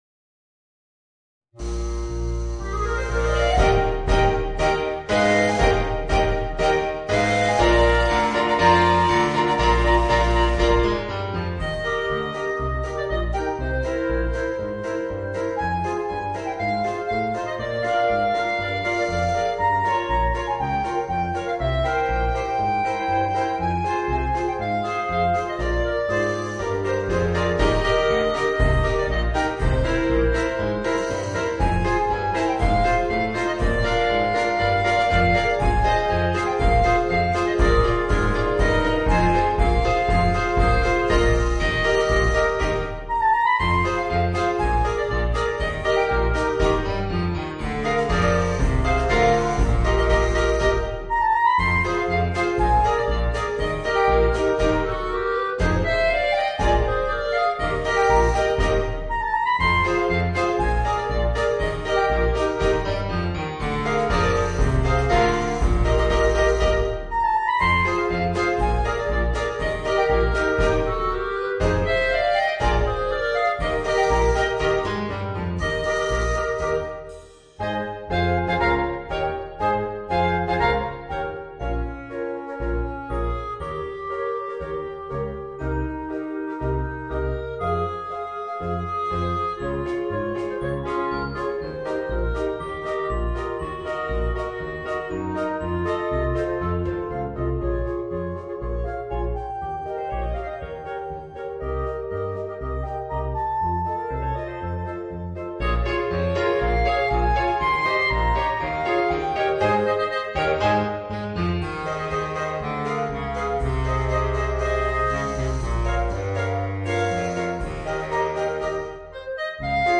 Voicing: 4 Clarinets and Rhythm Section